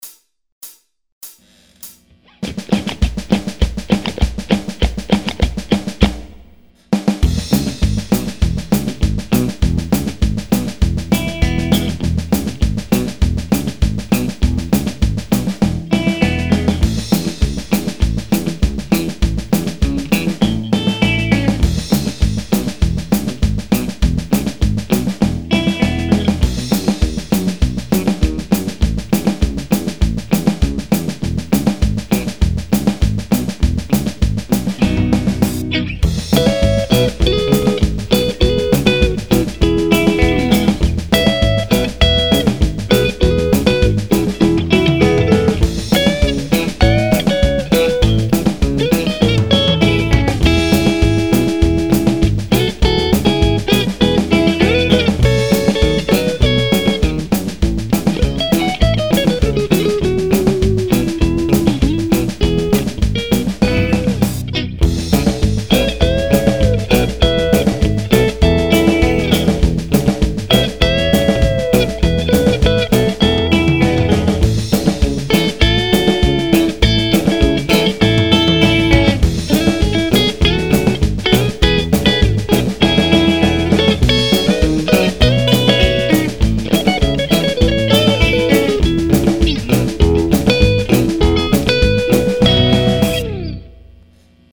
I composed all of these pieces and played all guitars. Drums, keyboards, and some bass guitars I programmed via computer and some bass guitars are my playing an actual bass guitar.
Orchard (my lame attempt at something psuedo-country). 2/2008